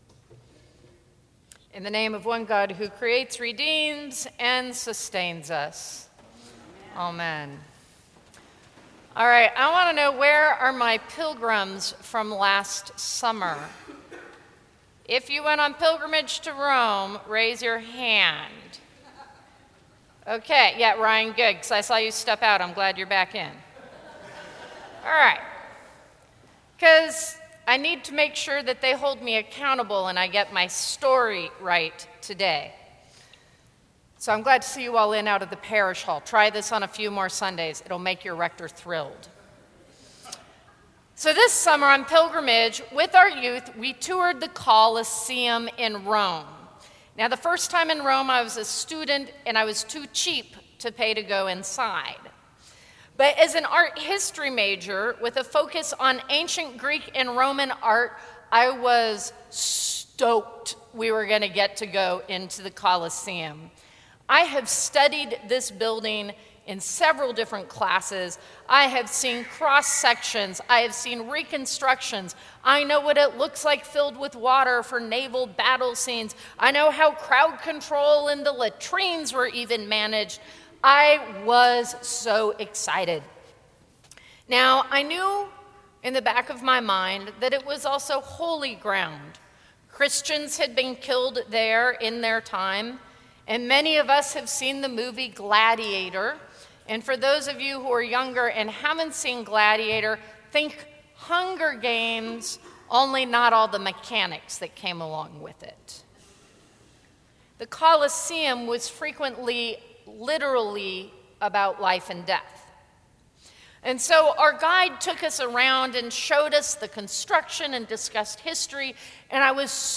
Sermons from St. Cross Episcopal Church 11/17/2013 Dec 17 2013 | 00:13:14 1x 00:00 / 00:13:14 Subscribe Share Apple Podcasts Spotify Overcast RSS Feed Share Link Embed